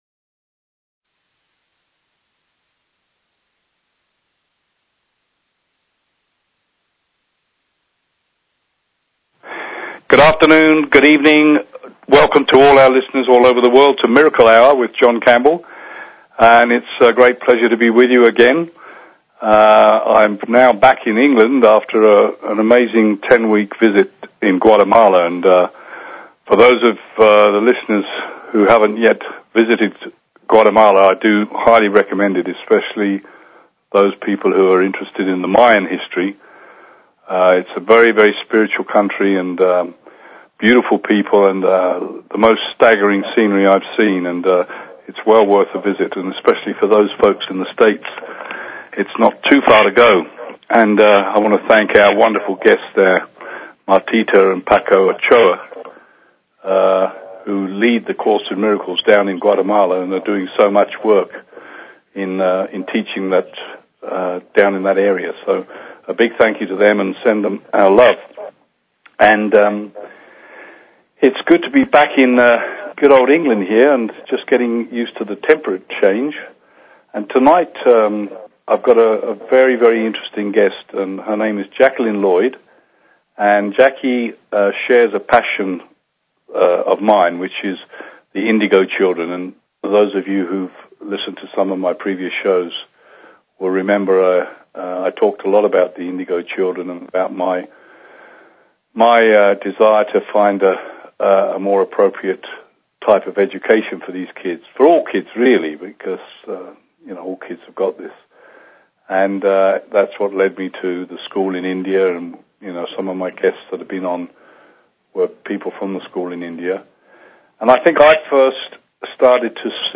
Talk Show Episode, Audio Podcast, Miracle_Hour and Courtesy of BBS Radio on , show guests , about , categorized as